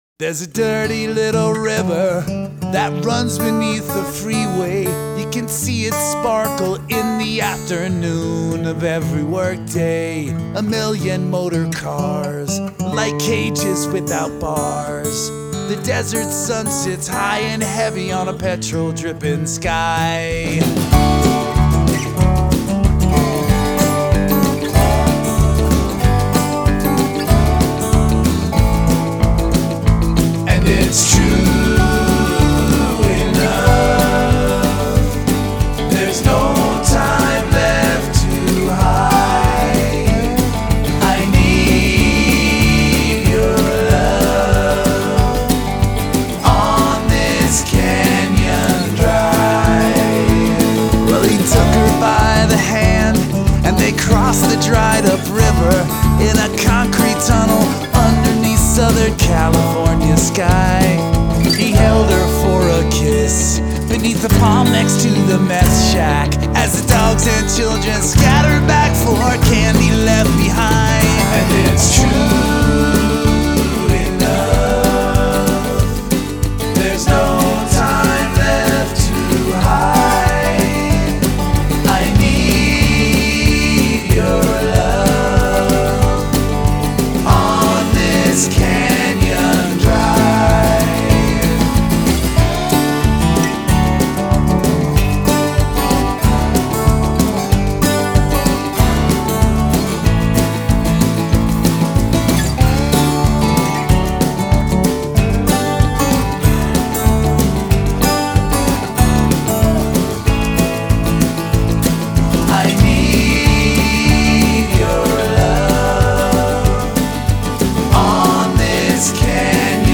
polished, subtlely hooky, sometimes amusing